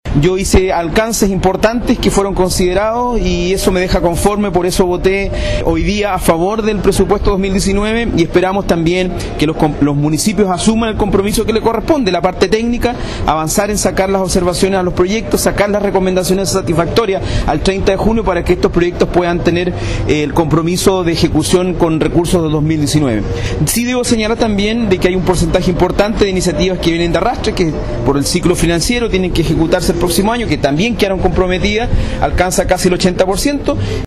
Francisco Cárcamo, Consejero Regional por Chiloé